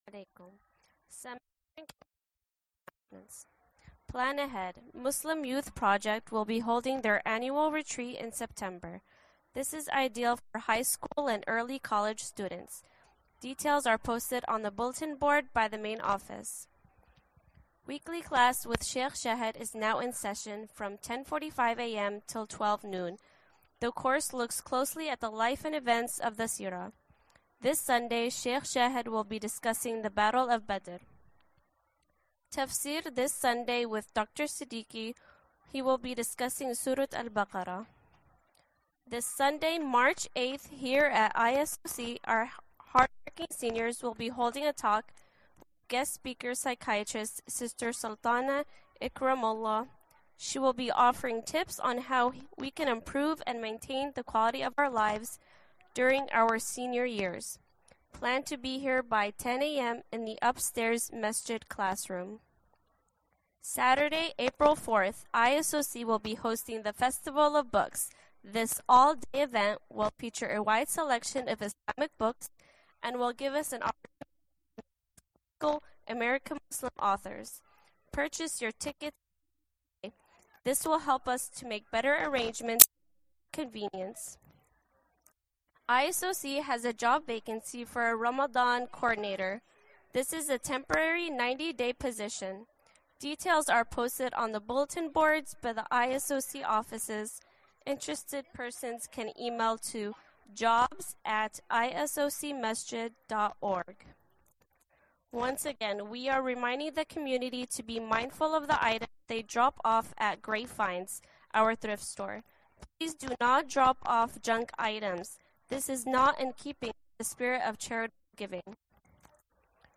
Khutbah 3/6/2020